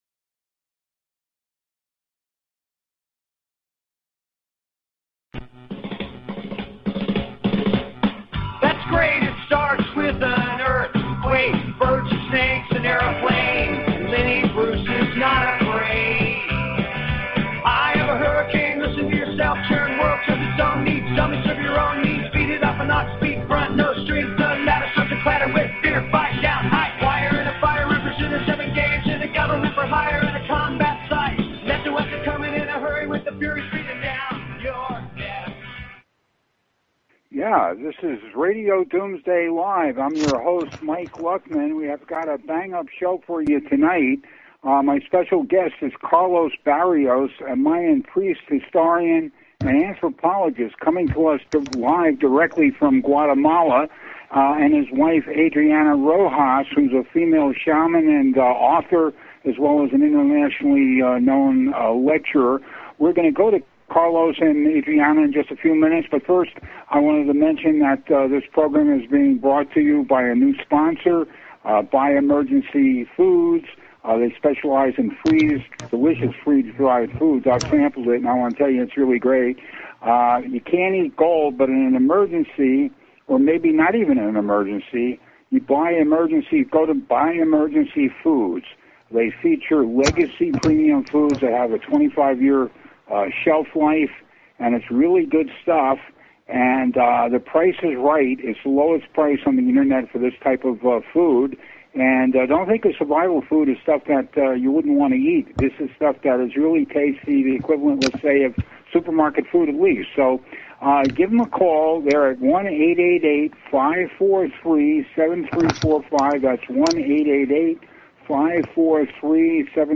Talk Show Episode, Audio Podcast, Starship_One_Radio and Courtesy of BBS Radio on , show guests , about , categorized as